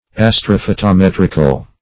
Meaning of astrophotometrical. astrophotometrical synonyms, pronunciation, spelling and more from Free Dictionary.